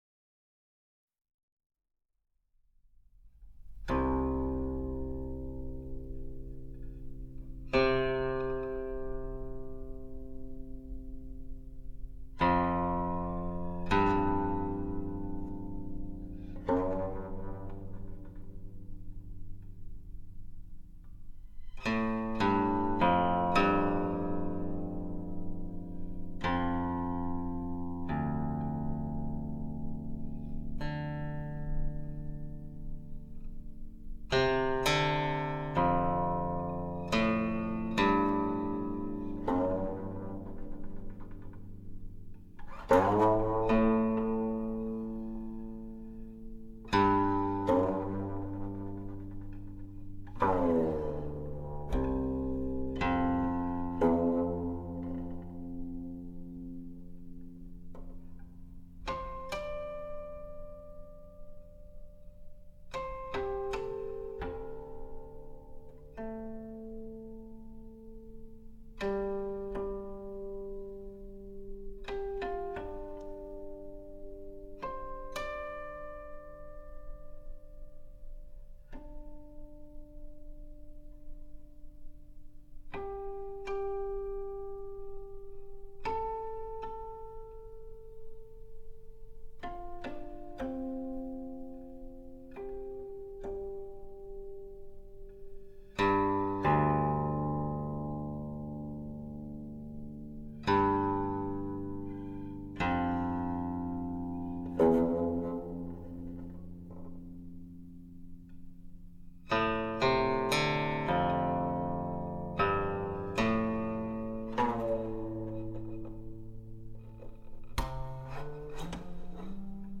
国宝级千年唐琴